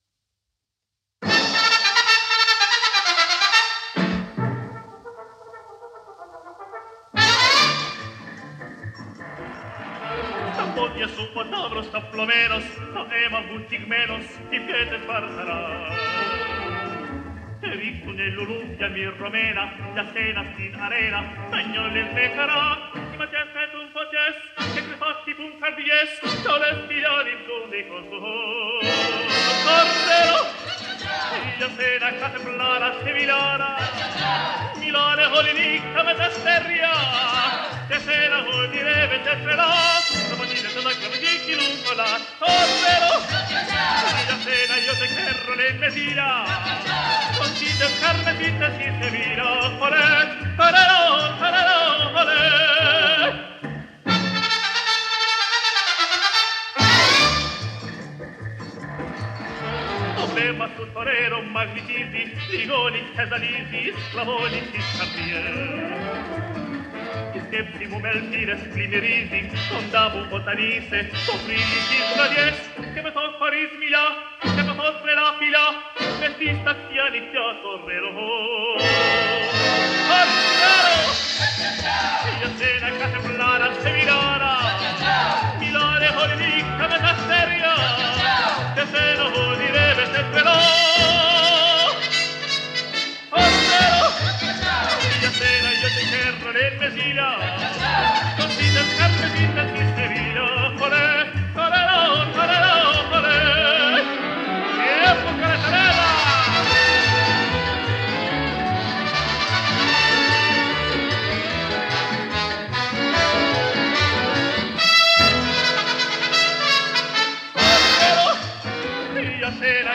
78 об.